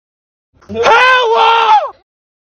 Ewoooo (Sound Effect For Comedy) 😂
Ewoooo-Comedy-sound-effect.mp3